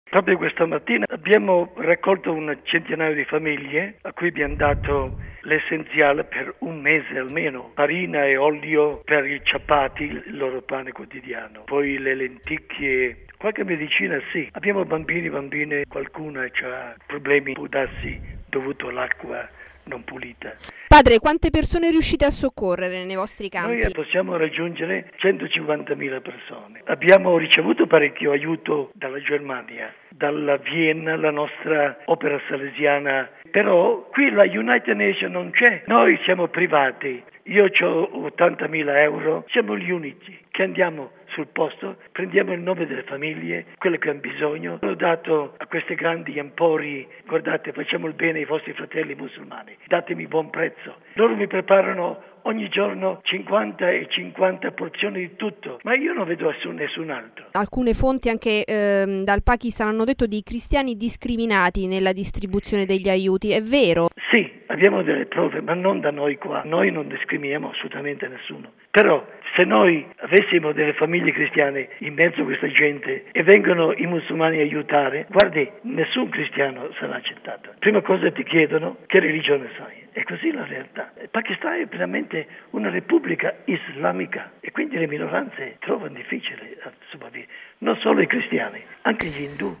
missionario salesiano